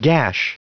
Prononciation du mot gash en anglais (fichier audio)
Prononciation du mot : gash